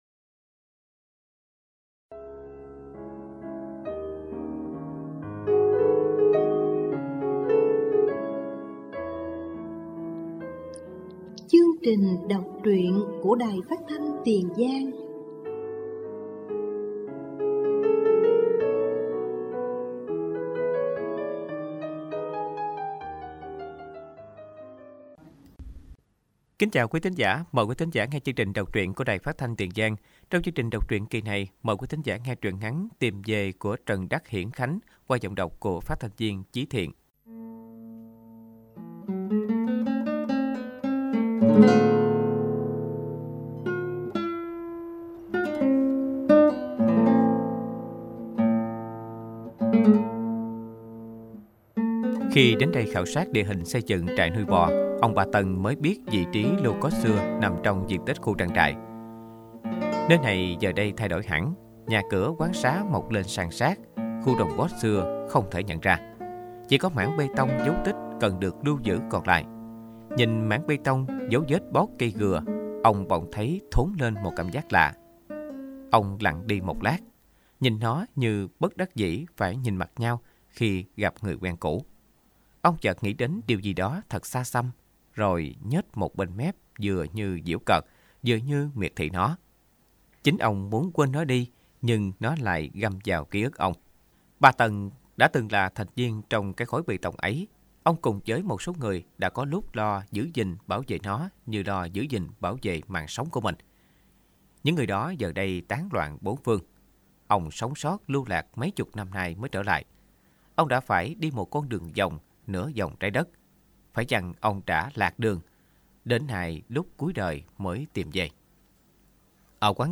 Đọc truyện “Tìm về”